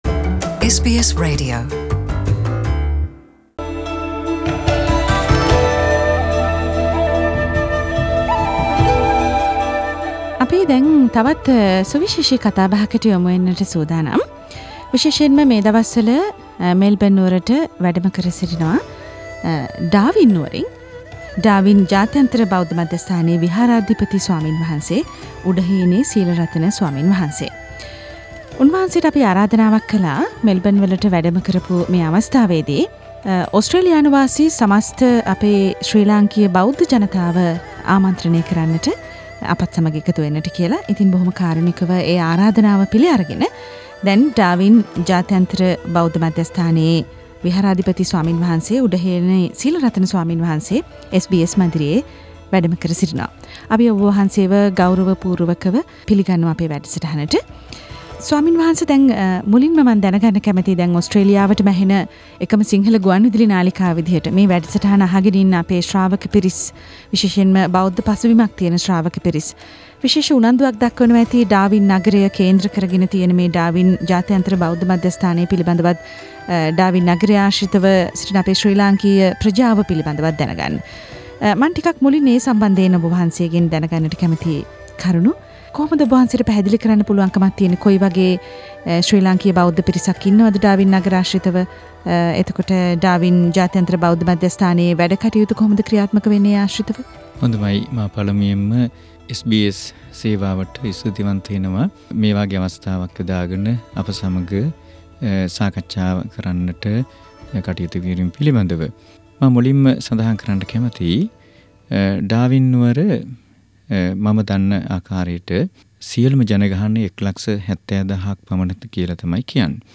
SBS Melbourne studios